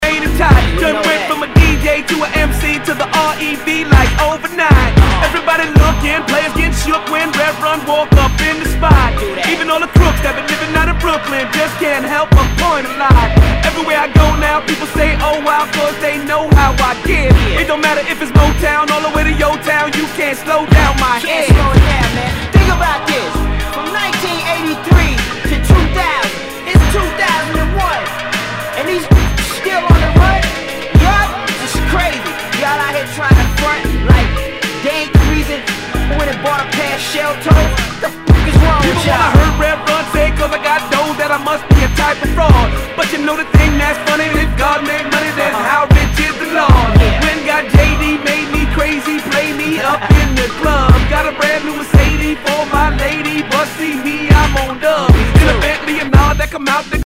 HIPHOP/R&B
ナイス！ヒップホップ！！